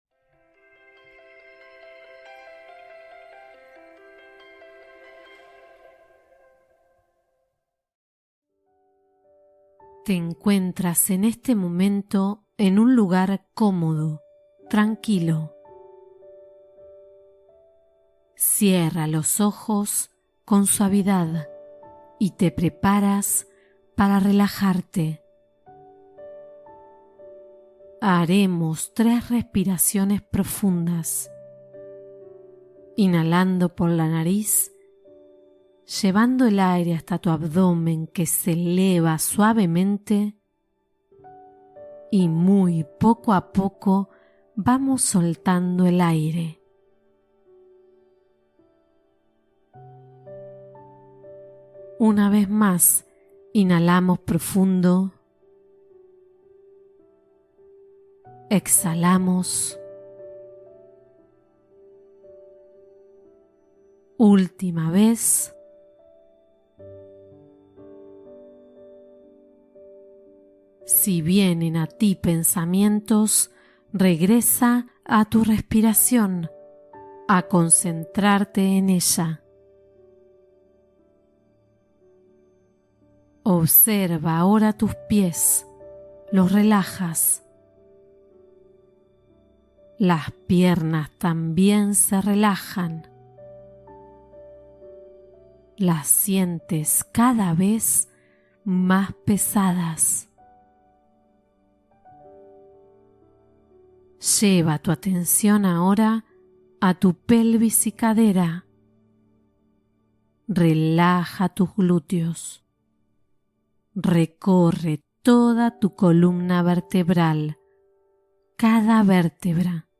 Atrae Abundancia: Meditación con Afirmaciones de Prosperidad